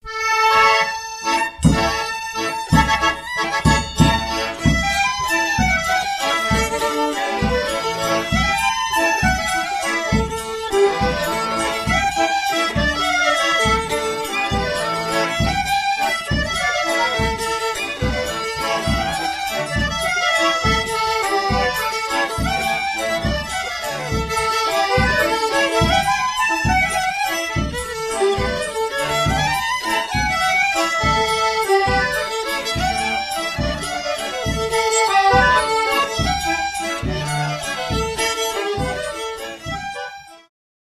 Oberek
Badania terenowe
harmonia
skrzypce
baraban